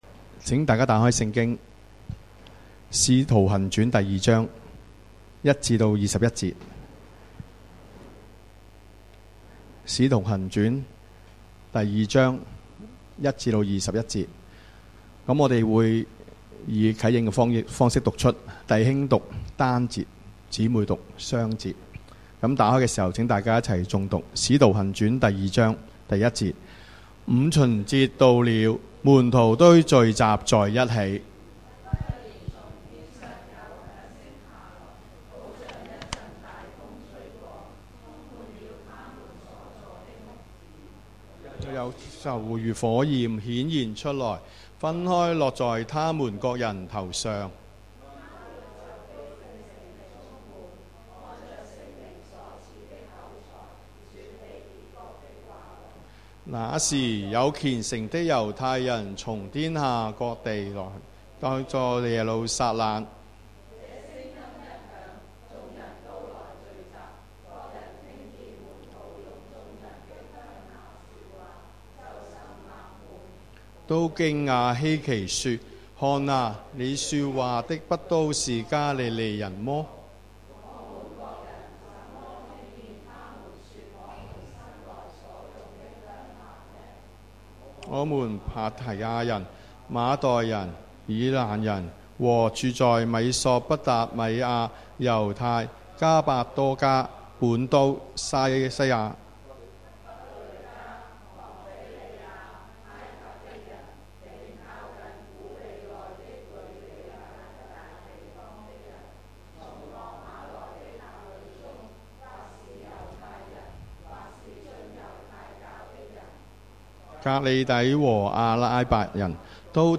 主日崇拜講道 – 我信聖靈